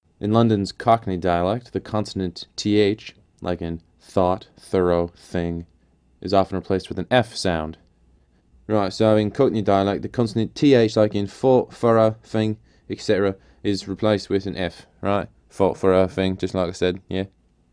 Just 200 miles away in London, the Cockney/”working class” dialect replaces the consonant /th/ (like in thought, thorough, thing, etc) with an /f/ sound.
cockneyLong.m4a